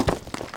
runAground.wav